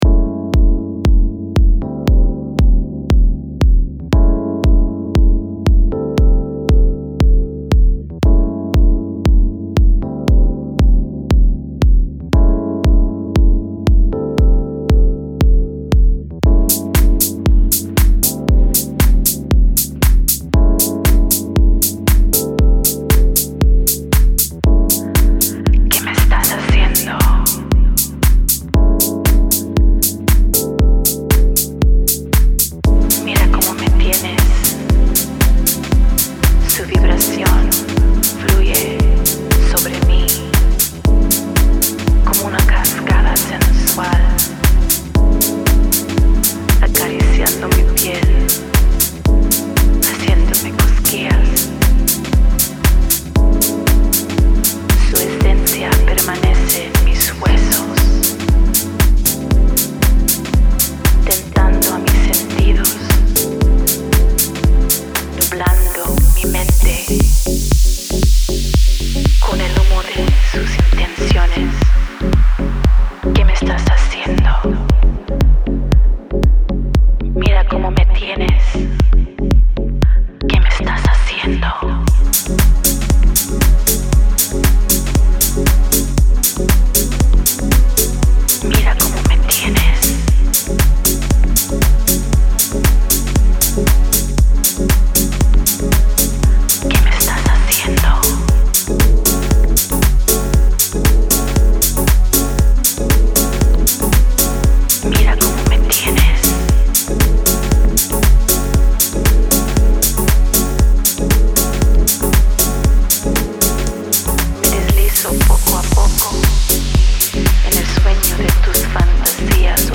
117 Chilled Latin House